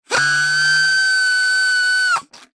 На этой странице собраны звуки, которые могут раздражать кошек и собак: высокочастотные сигналы, резкие шумы, ультразвук.